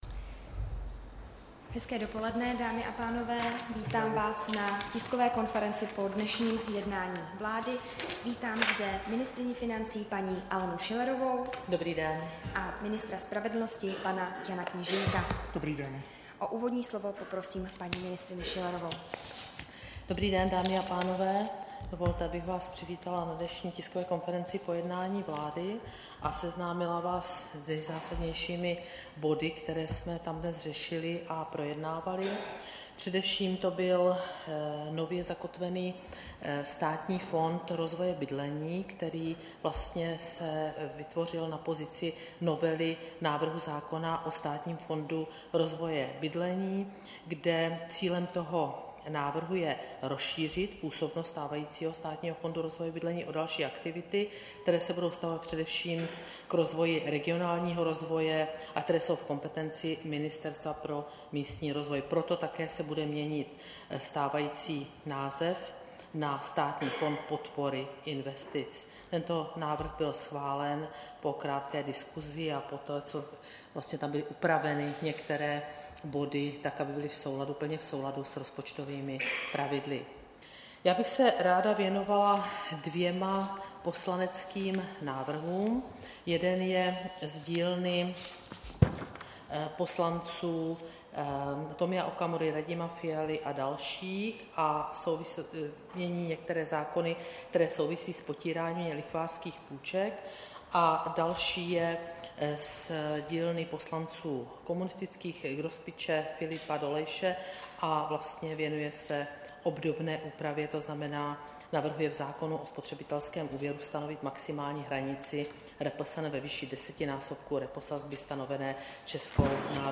Tisková konference po jednání vlády, 8. února 2019